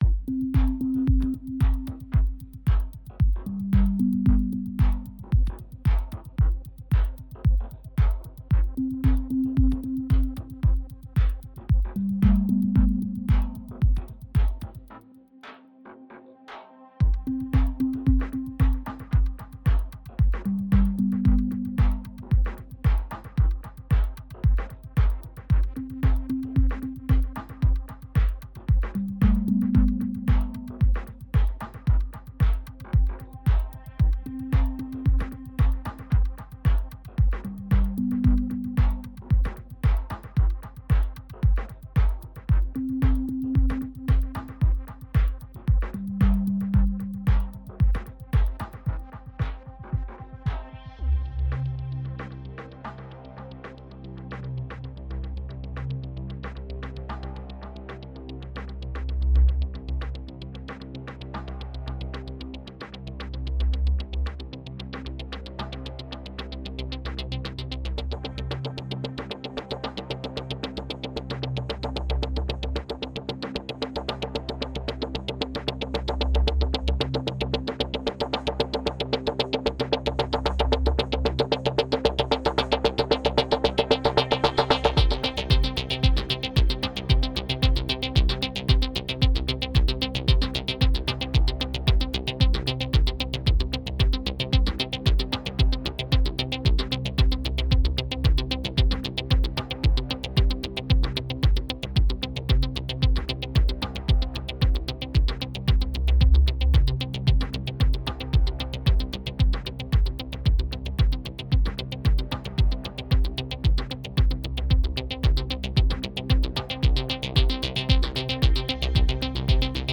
Think I'll add like 2-4 more layers, some more variation on the drums / more drums and call it a day.